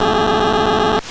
AlectoV1 ISM band Weather sensor